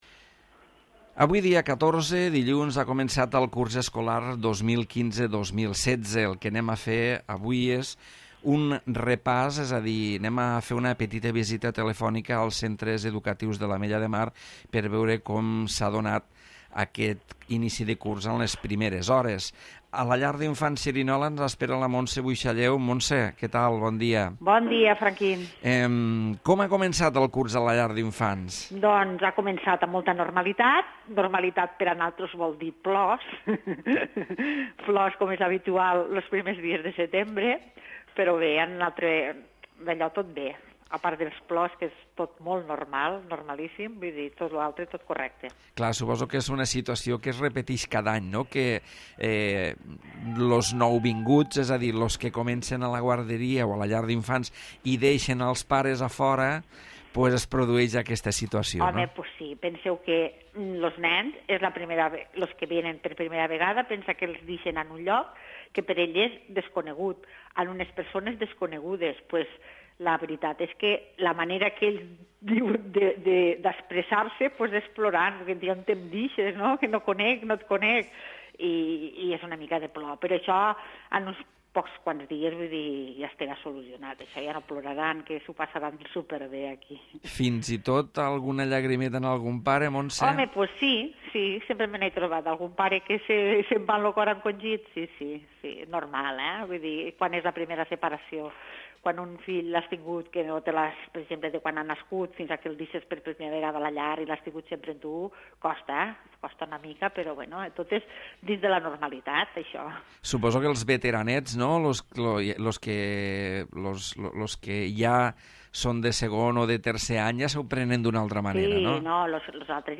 L'Entrevista
Roda de connexions amb els centres educatius de l'Ametlla de Mar per conèixer com s'ha desenvolupat l'inici del curs.